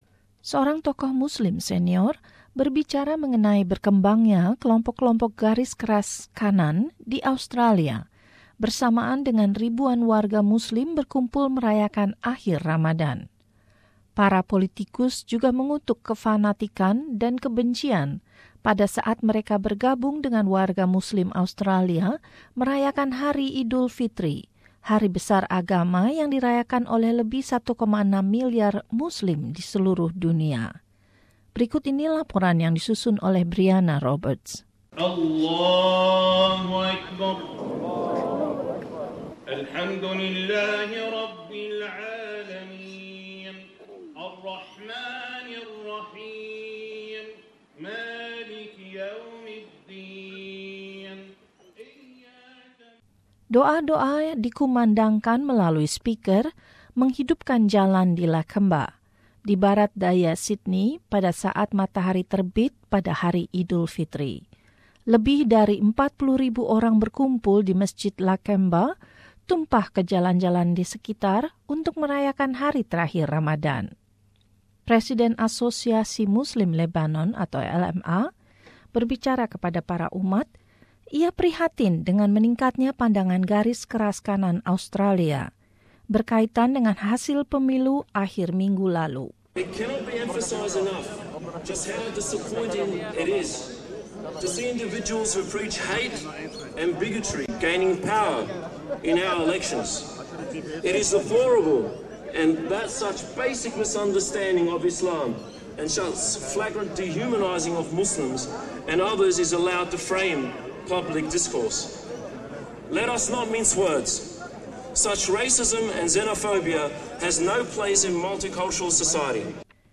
Doa-doa dikumandangkan melalui speaker menghidupkan jalan di Lakemba, di Barat Daya Sydney pada saat matahari terbit pada hari Idul Fitri.